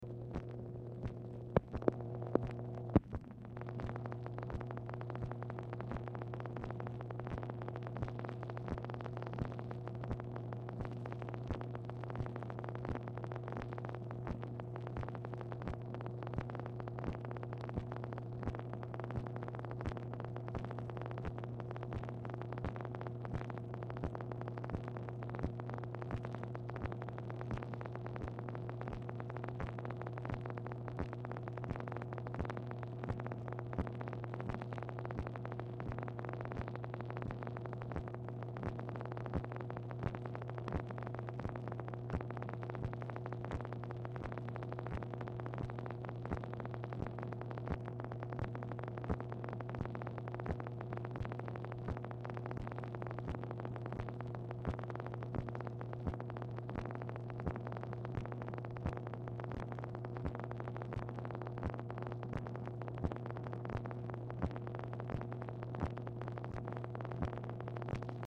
Telephone conversation # 10429, sound recording, MACHINE NOISE, 7/25/1966, time unknown | Discover LBJ
Format Dictation belt
Specific Item Type Telephone conversation